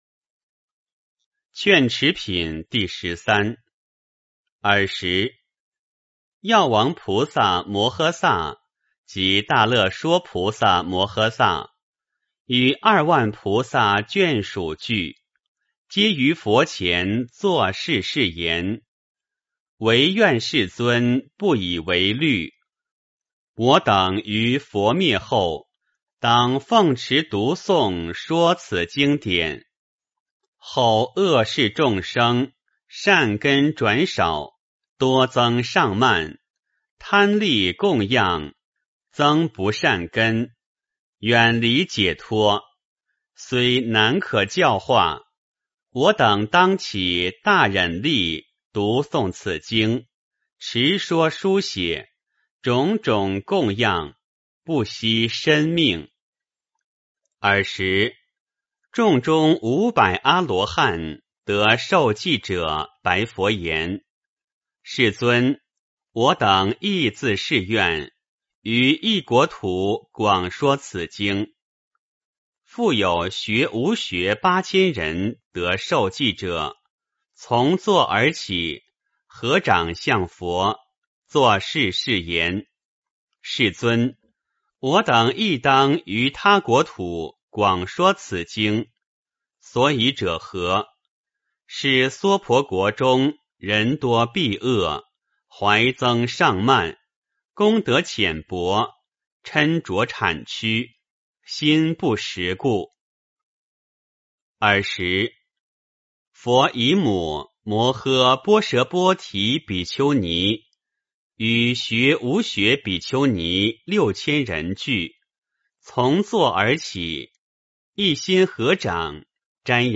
法华经-劝持品第十三 诵经 法华经-劝持品第十三--未知 点我： 标签: 佛音 诵经 佛教音乐 返回列表 上一篇： 法华经-提婆达多品第十二 下一篇： 法华经-从地涌出品第十五 相关文章 锣鼓呈祥--新韵传音 锣鼓呈祥--新韵传音...